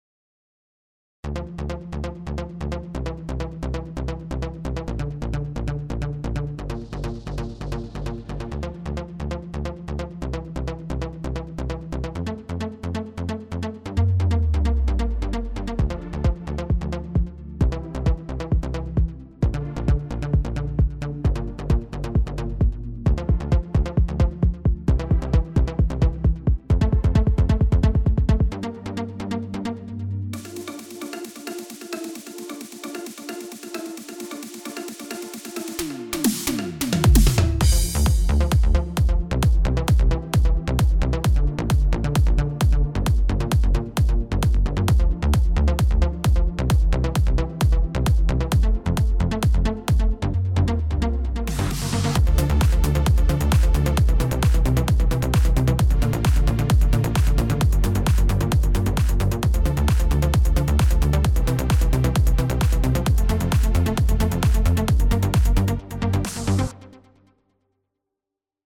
מקצב טוב ,אבל זה לא קשור זה נשמע סאונד של מגפון של האוטו ארטיקים